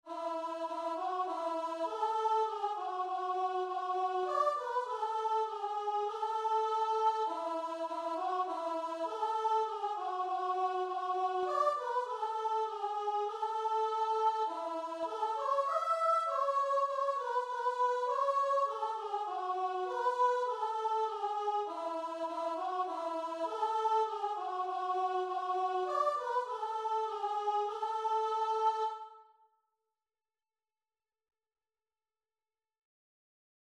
3/4 (View more 3/4 Music)
Classical (View more Classical Guitar and Vocal Music)